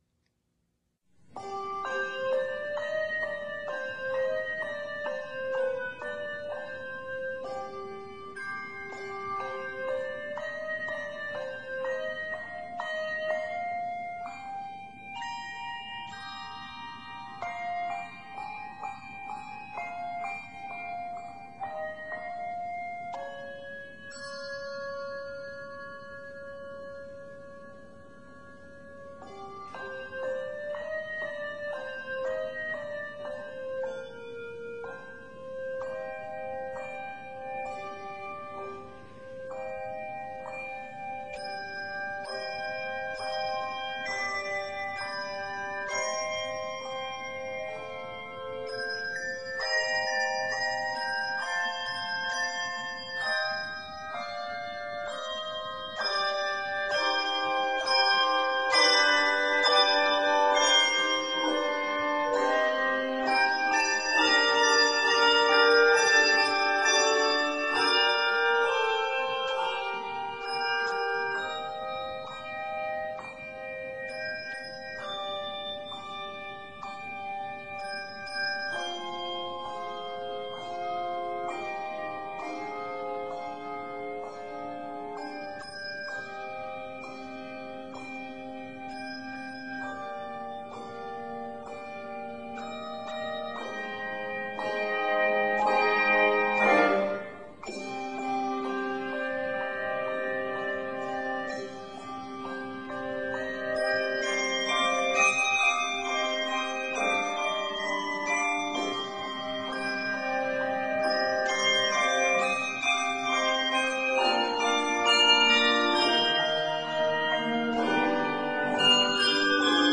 A sensitive piece ideally suited for the Lenten season.